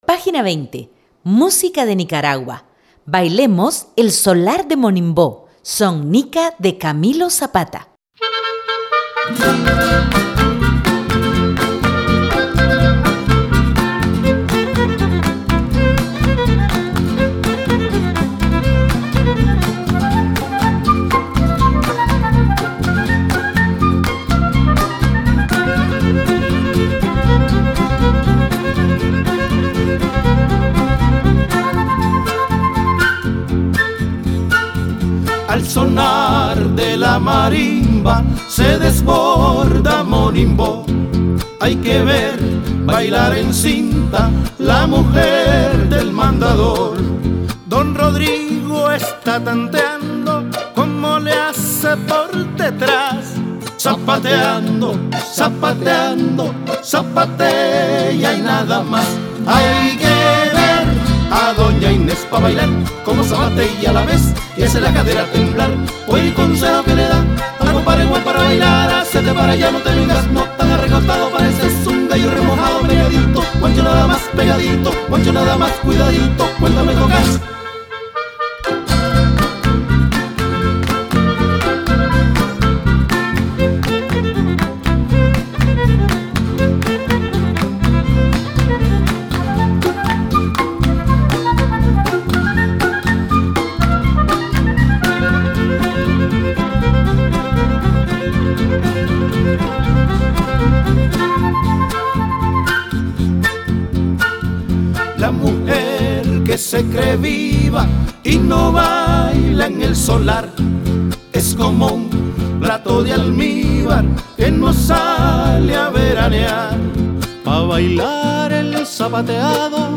Son Nica